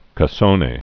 (kə-sōnā, -nē)